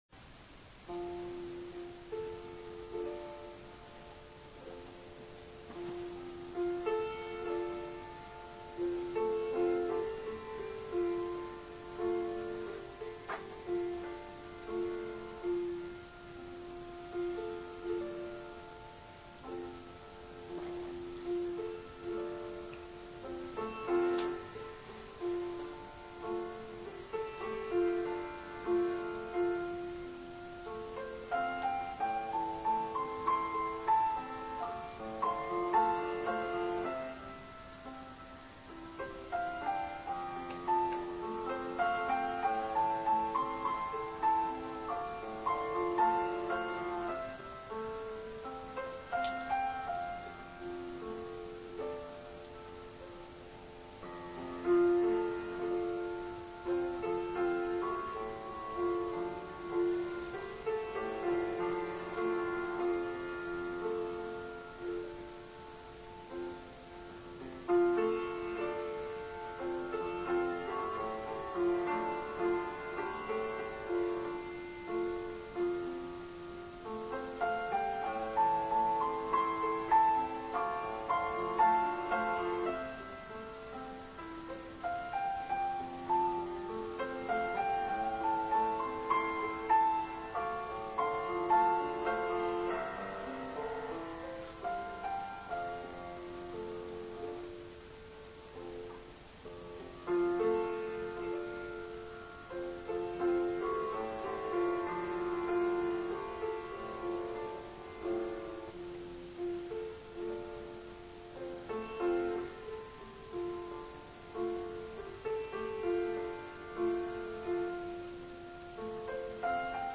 Musical Prelude